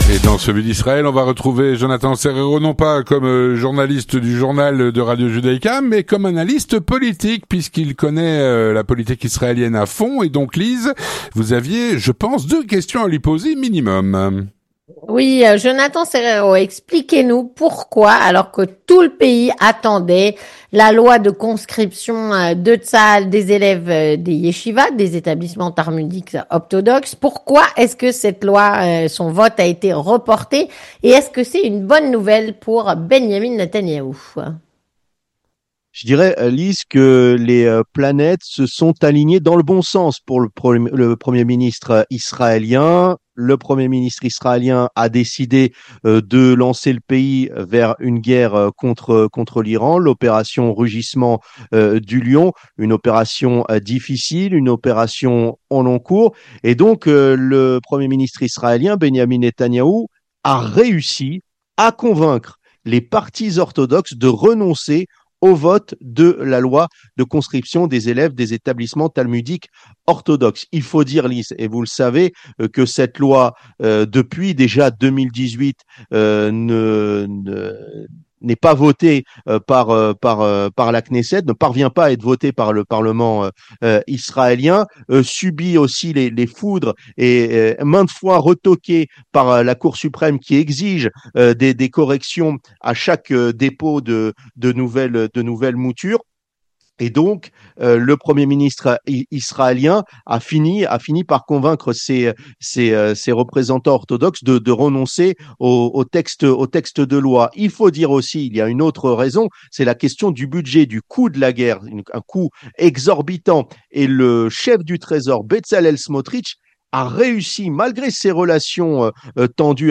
L'analyse et les explications